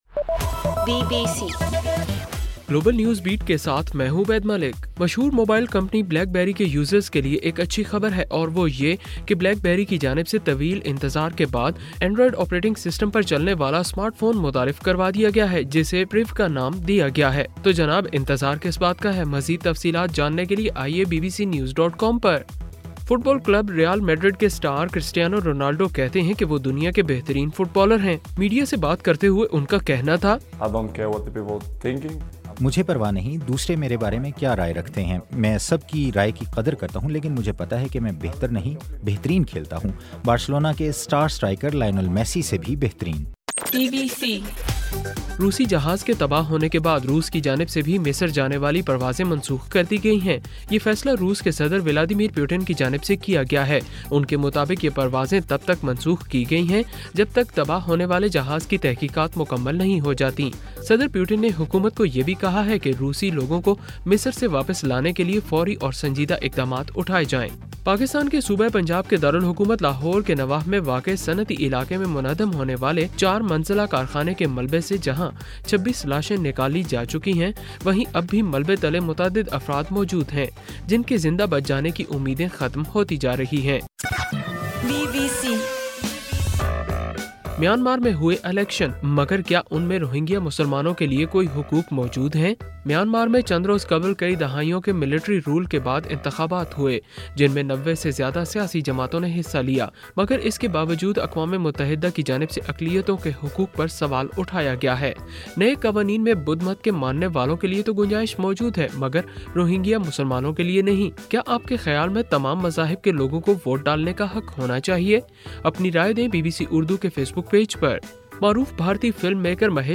نومبر 6: رات 10 بجے کا گلوبل نیوز بیٹ بُلیٹن